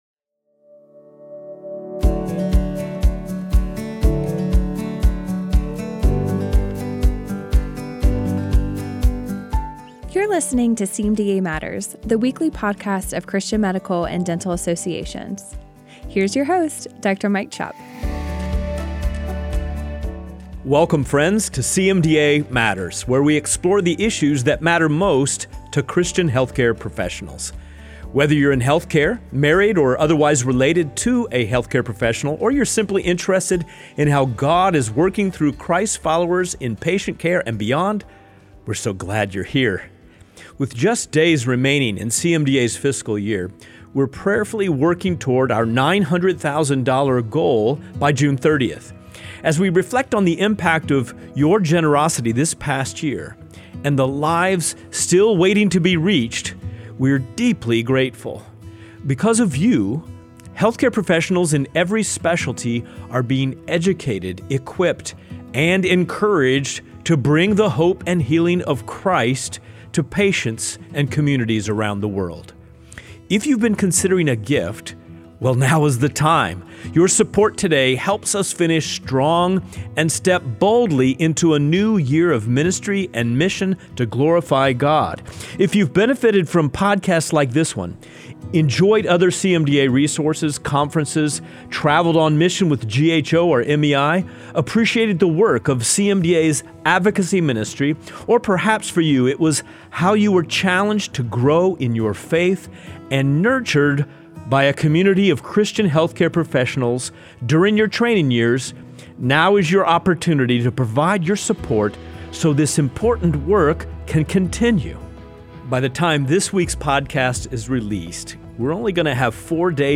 Recorded live at the Colson Center National Conference in Louisville, KY, this episode features Christian healthcare professionals — and one spouse — who are living out their faith in some of today’s most complex medical and cultural spaces.